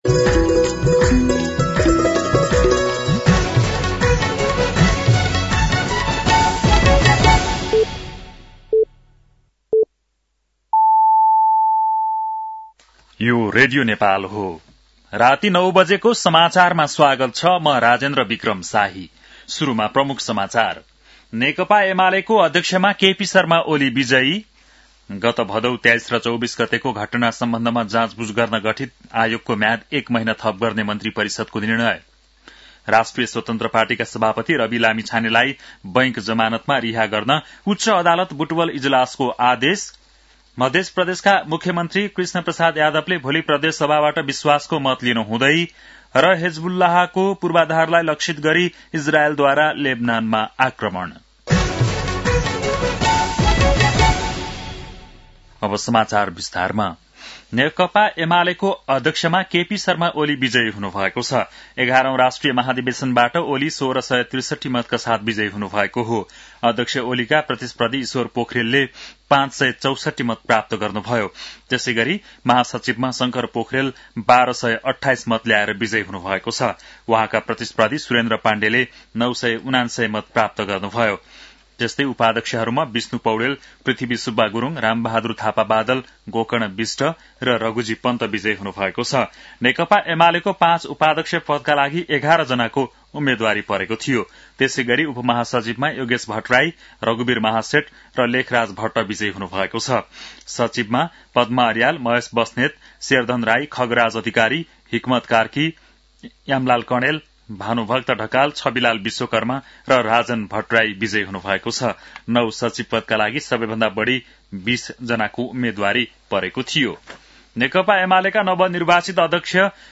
बेलुकी ९ बजेको नेपाली समाचार : ३ पुष , २०८२
9-PM-Nepali-NEWS-9-3.mp3